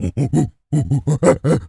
gorilla_chatter_01.wav